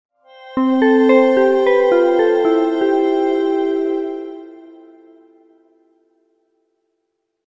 Sonido para iniciar presentación: